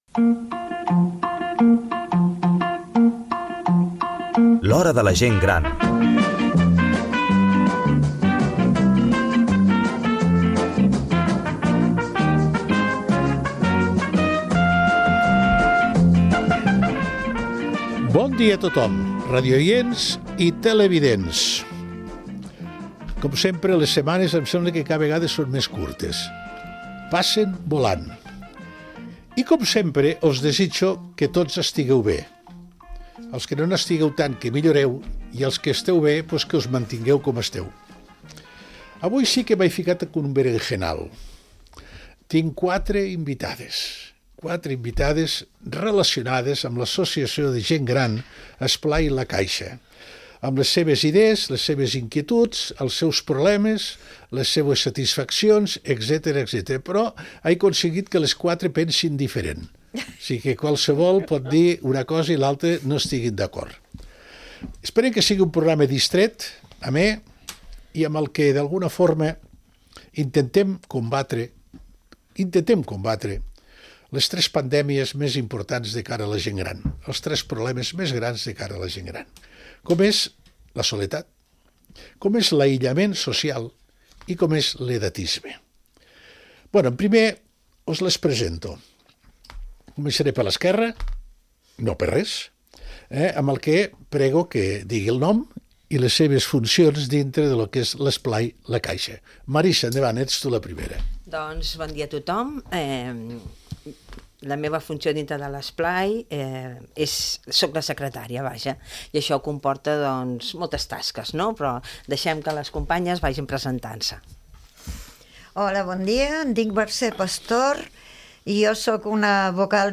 Un programa específic per a la Gent Gran